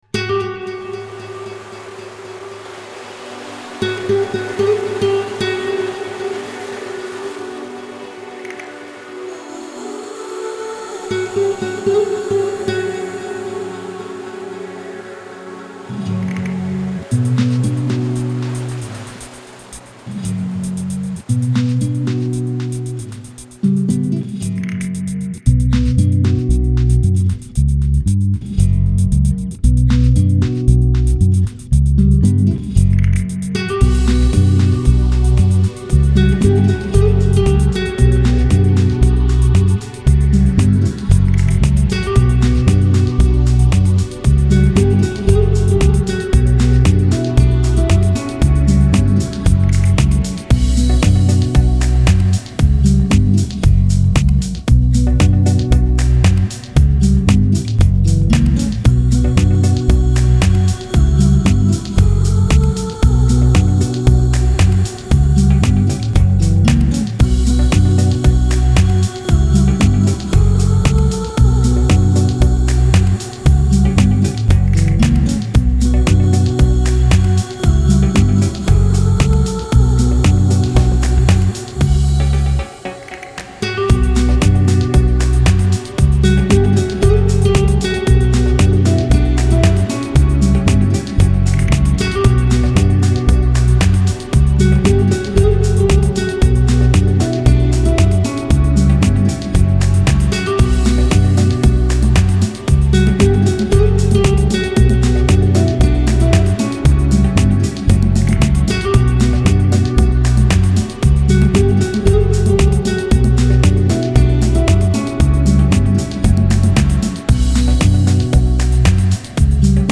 Genre: Chillout, Lounge, Downtempo.